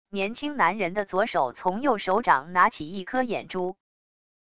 年轻男人的左手从右手掌拿起一颗眼珠wav音频